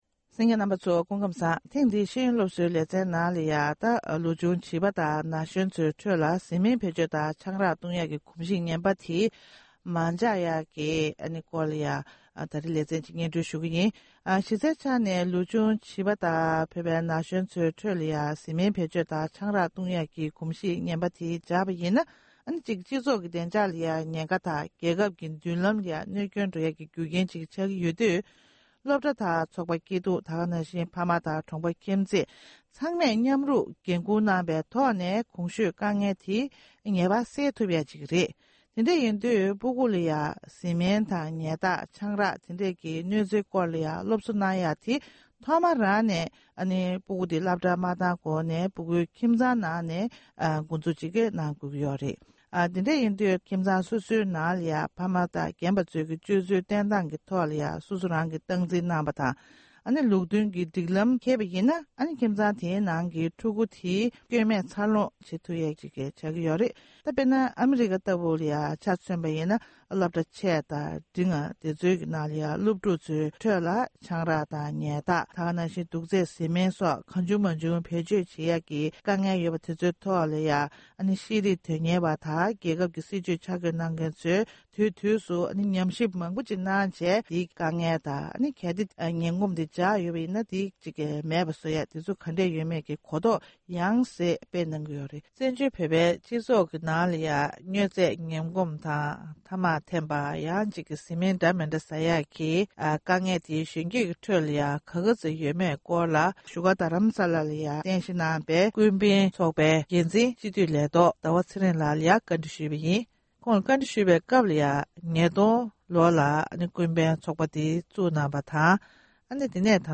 བཟི་སྨན་ངན་གོམས་སྔོན་འགོག་དང་བཅོས་ཐབས། ལེ་ཚན་དང་པོ། སྒྲ་ལྡན་གསར་འགྱུར།